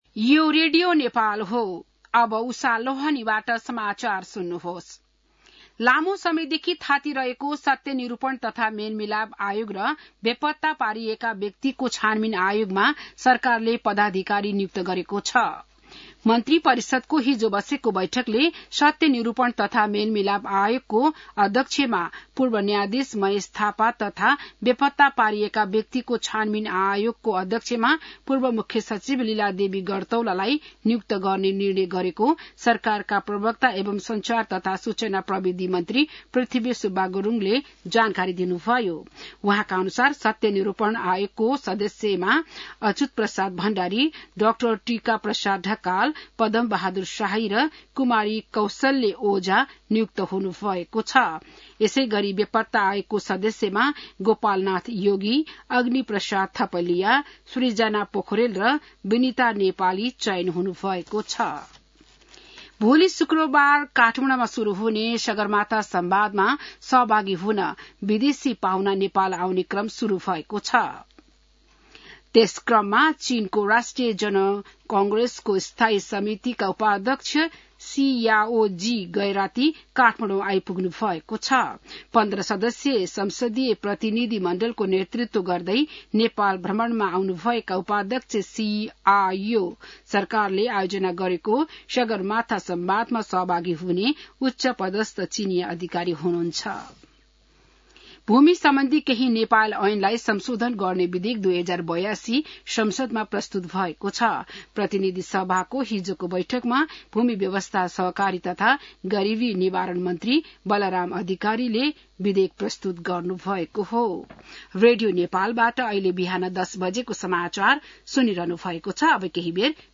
बिहान १० बजेको नेपाली समाचार : १ जेठ , २०८२